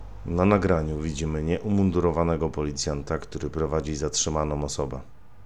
Nagranie audio Audiodeskrypcja do filmu